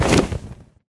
Sound / Minecraft / mob / enderdragon / wings3.ogg
wings3.ogg